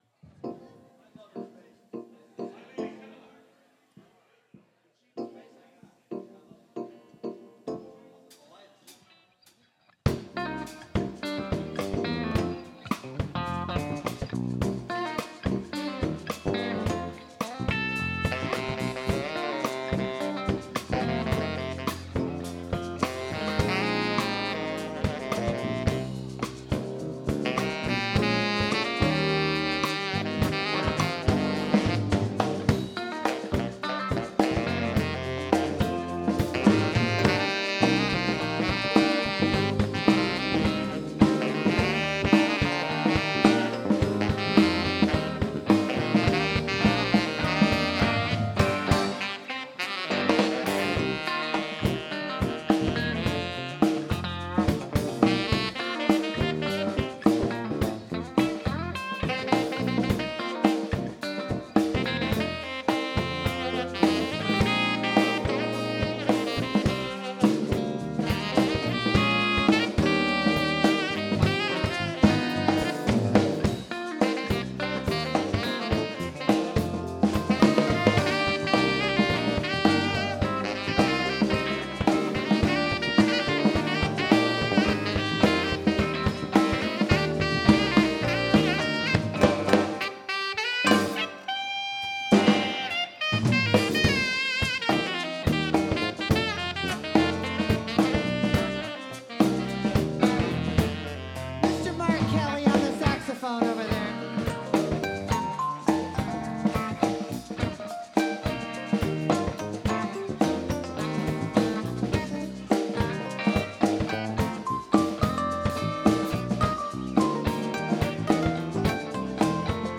Live on New Year's Eve 2024
instrumental